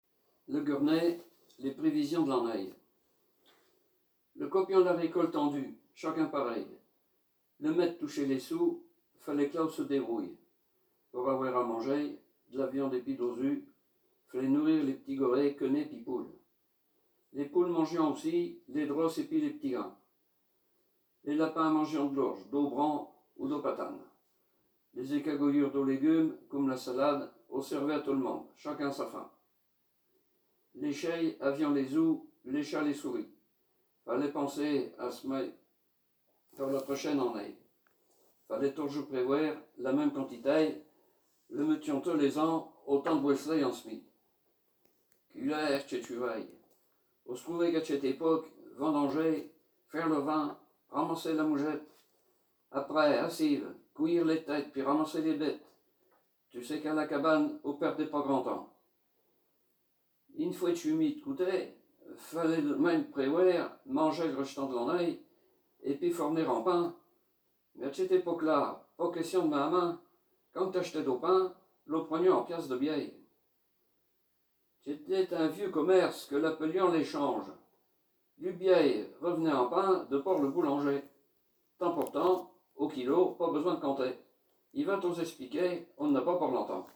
Genre poésie
Poésies en patois
Catégorie Récit